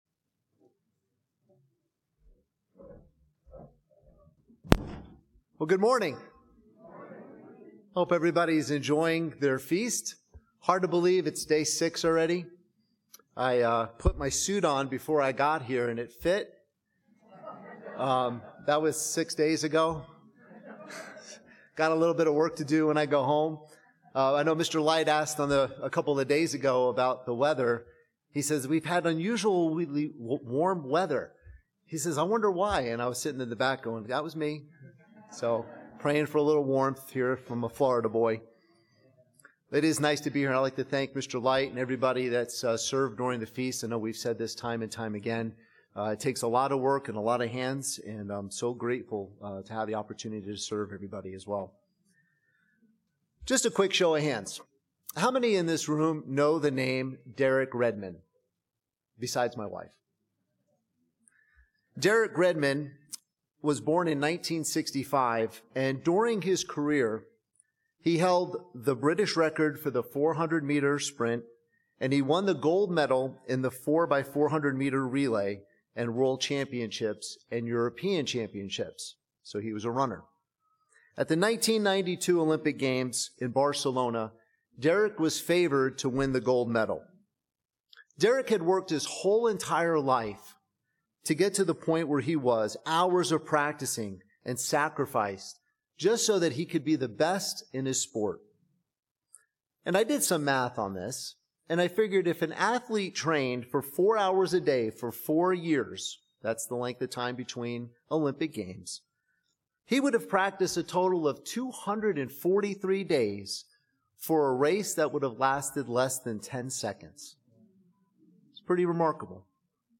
Split Sermon 1 - Day 6 - Feast of Tabernacles - Klamath Falls, Oregon
This sermon was given at the Klamath Falls, Oregon 2024 Feast site.